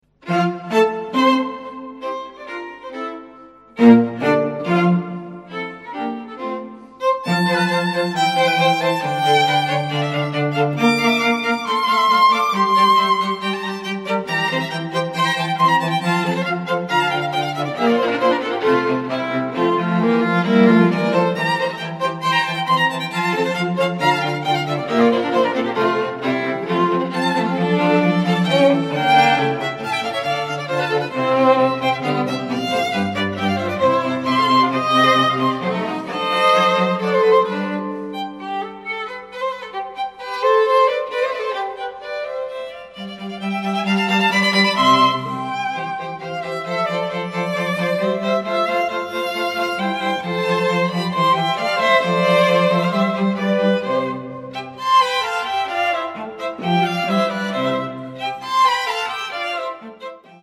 Струнный квартет НОНА. Живая музыка для ВАС!